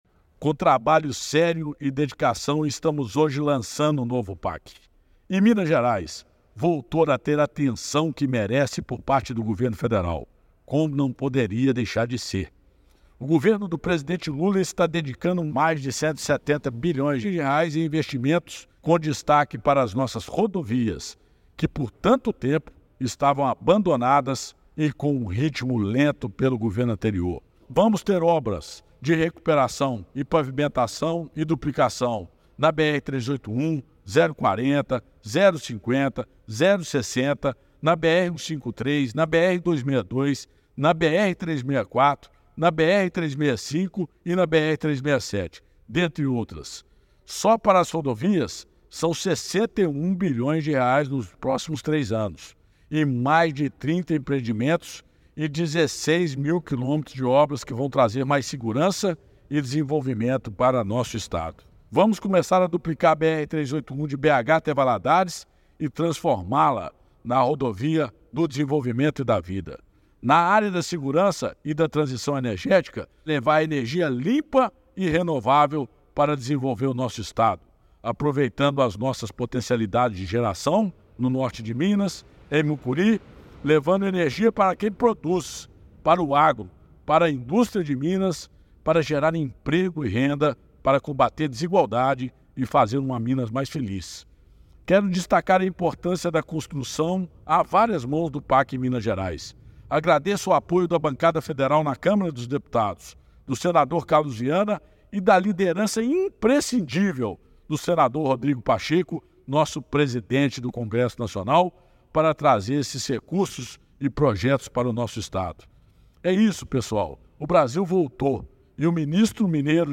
Confira o áudio do Ministro Alexandre Silveira:
Audio-Ministro-Alexandre-Silveira-PAC-1.mp3